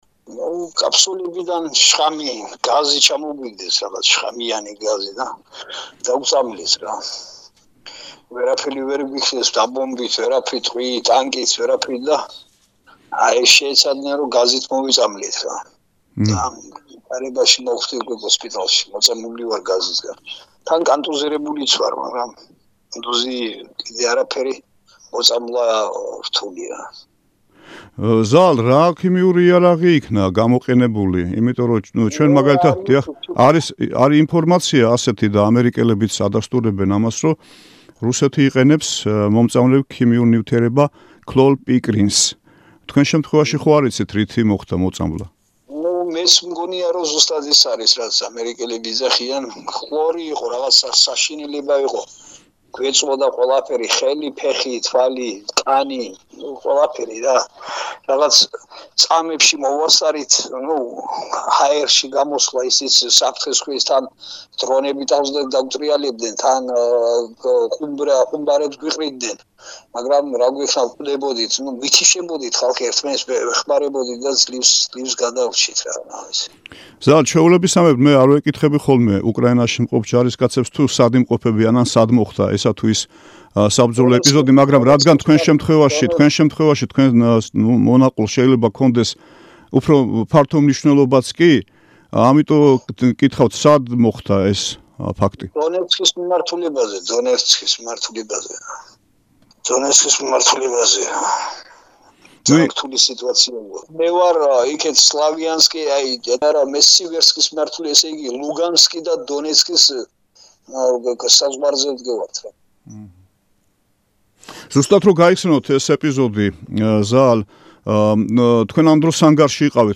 ინტერვიუ
რომელიც რადიო თავისუფლებას ტელეფონის საშუალებით დაუკავშირდა ქალაქ დნეპრის საავადმყოფოდან